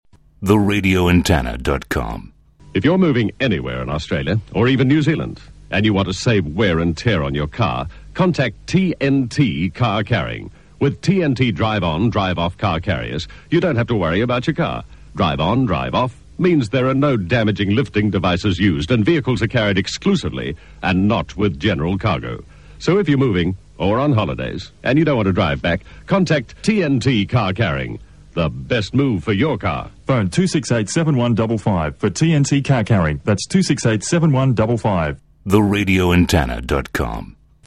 This was recorded from FM 104 Brisbane 1986, the tag was me and i was stoked that i had Lawsy preceeding me, such a smooth delivery, oh from John Laws that is …hehe